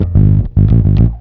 FINGERBSS1-R.wav